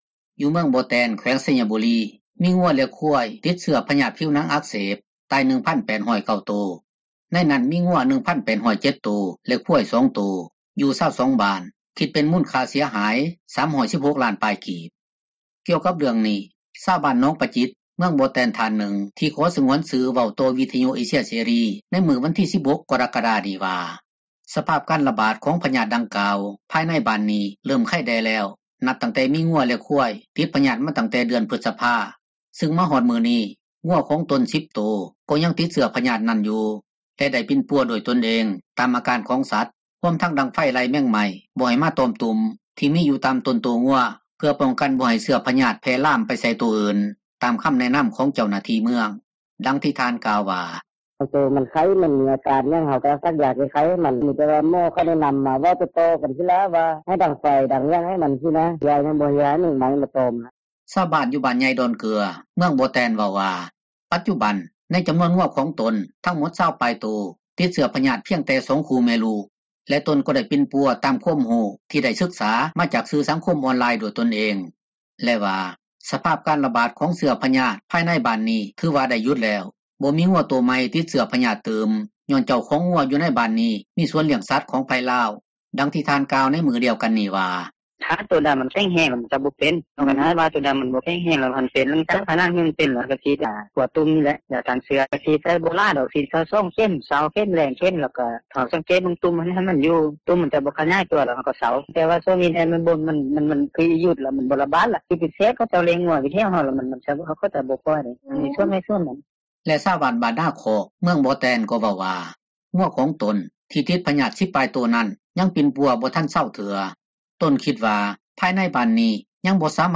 ກ່ຽວກັບເຣື່ອງນີ້ ຊາວບ້ານໜອງປະຈິດ ເມືອງບໍ່ແຕນ ທ່ານນຶ່ງທີ່ຂໍສງວນຊື່ ເວົ້າຕໍ່ວິທຍຸເອເຊັຽເສຣີໃນມື້ວັນທີ 16 ກໍຣະກະດາ ນີ້ວ່າ ສະພາບການຣະບາດ ຂອງພຍາດດັ່ງກ່າວ ພາຍໃນບ້ານນີ້ ເຣີ່ມໄຄແດ່ແລ້ວ ນັບຕັ້ງແຕ່ມີງົວ ແລະຄວາຍ ຕິດພຍາດມາຕັ້ງແຕ່ເດືອນ ພຶສພາ ຊຶ່ງມາຮອດມື້ນີ້ ງົວຂອງຕົນ 10 ໂຕ ກໍຍັງຕິດເຊື້ອພຍາດນັ້ນຢູ່ ແລະໄດ້ປິ່ນປົວ ດ້ວຍຕົນເອງຕາມອາການສັດຮວມທັງດັງໄຟ ໄລ່ແມງໄມ້ ບໍ່ໃຫ້ຕອມຕຸ່ມ ທີ່ມີຢູ່ຕາມຕົນໂຕງົວ ເພື່ອປ້ອງກັນບໍ່ໃຫ້ເຊື້ອພຍາດແຜ່ລາມໄປໃສ່ໂຕອື່ນ ຕາມຄໍາແນະນໍາຂອງ ເຈົ້າໜ້າທີ່ ເມືອງ ດັ່ງທີ່ທ່ານກ່າວວ່າ: